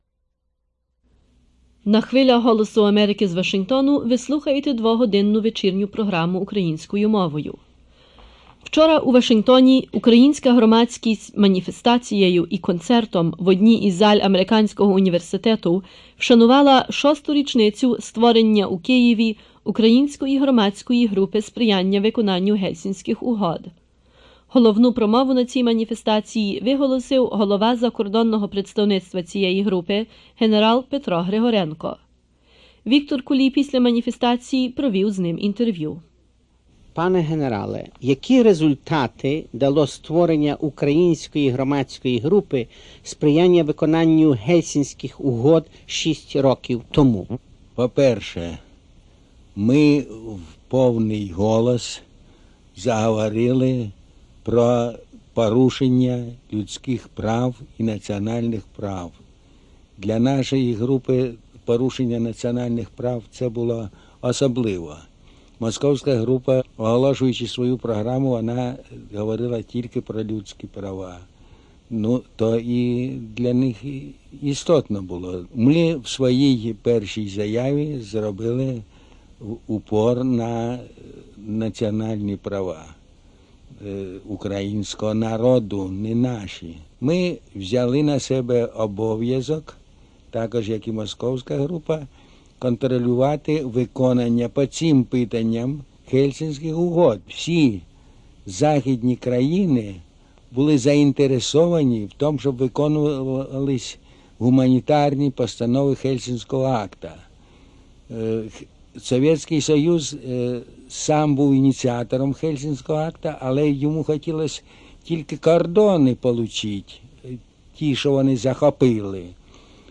Interview
Protest in front of the UN General Assembly against the imprisonment and persecution of the Helsinki Group by the Soviet Government